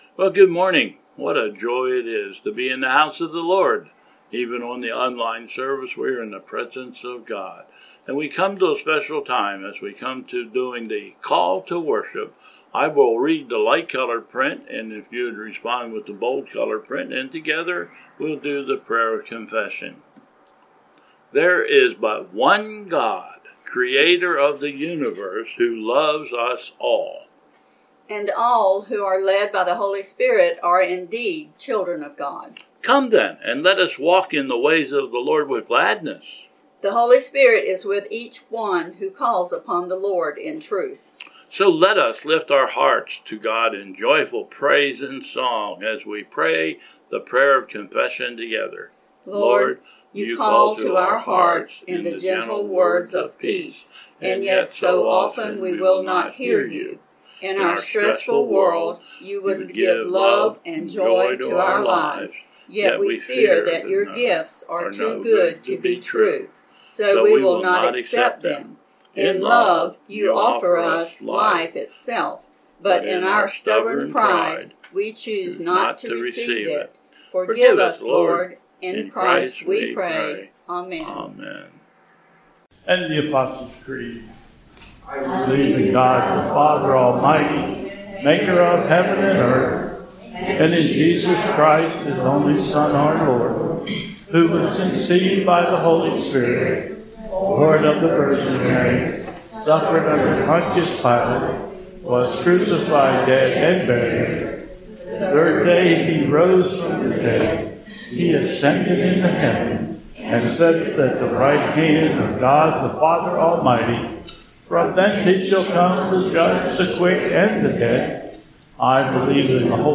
Bethel 04/11/21 Service
. Slides . Call to Worship, Apostle's Creed and Gloria Patria . (02:51)